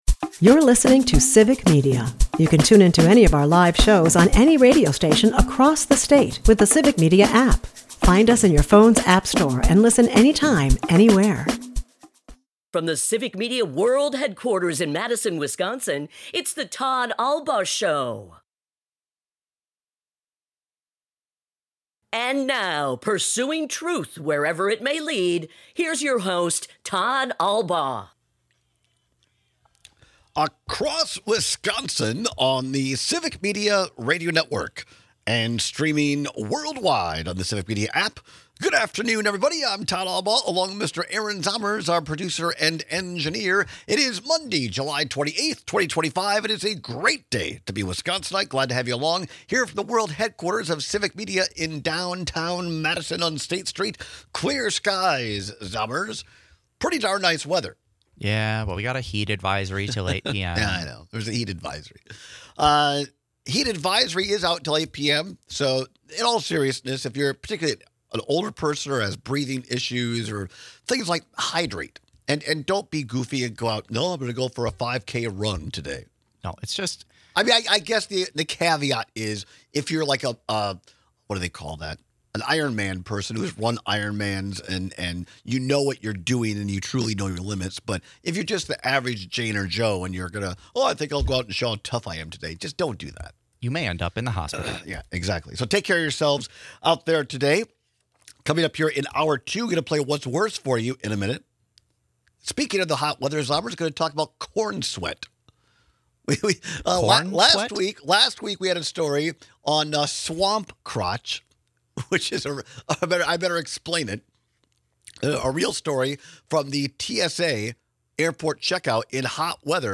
We take your calls and texts on these annoying summer pests.